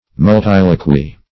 Multiloquy \Mul*til"o*quy\, n.